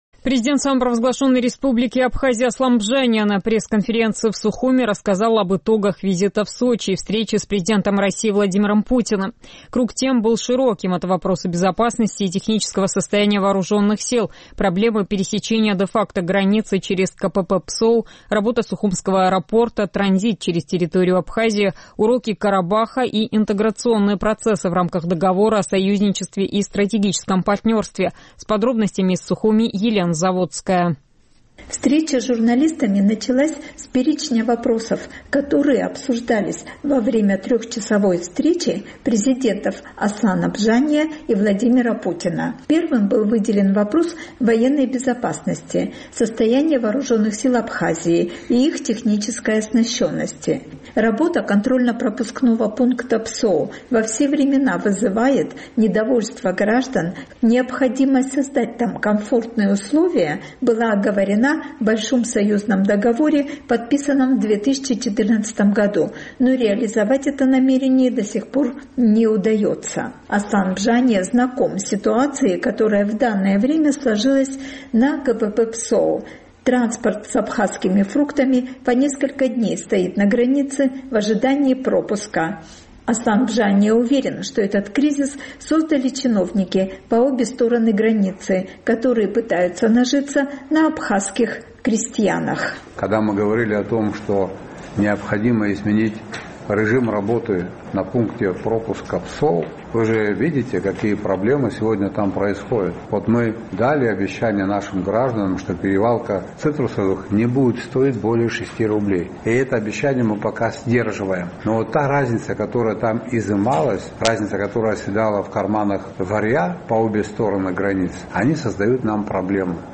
Президент Абхазии Аслан Бжания на пресс-конференции в Сухуме рассказал об итогах визита в Сочи и встречи с президентом России Владимиром Путиным.